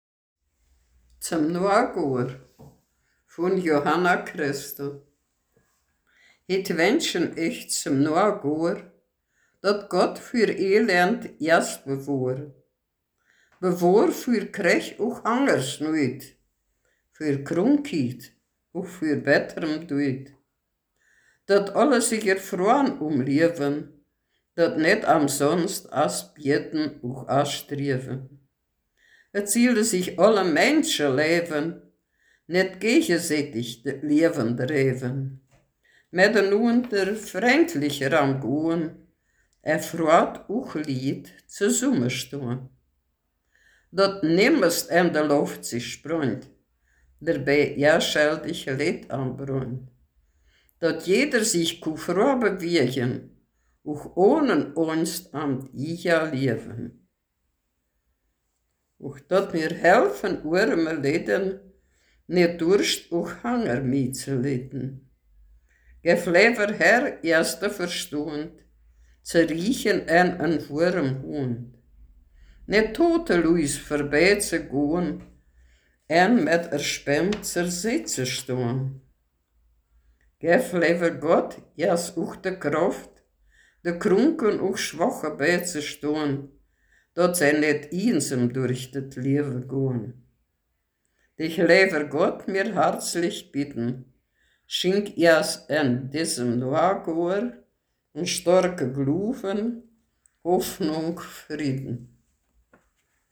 Ortsmundart: Hetzeldorf